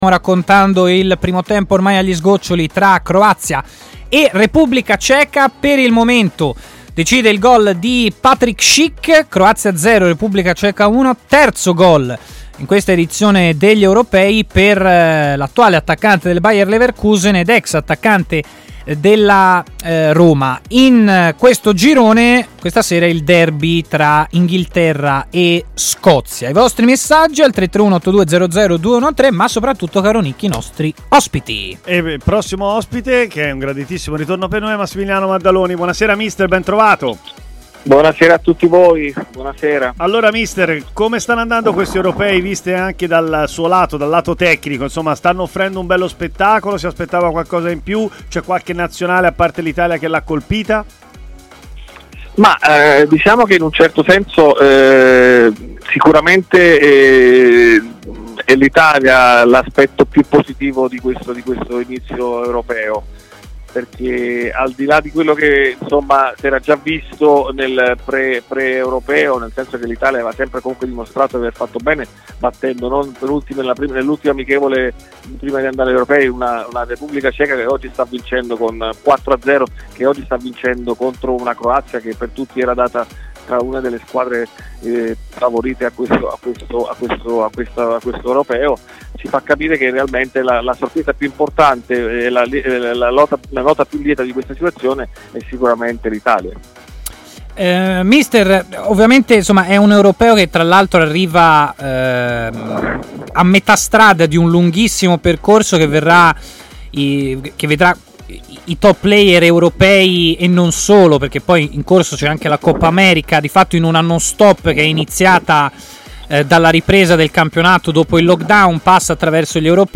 trasmissione di TMW Radio condotta.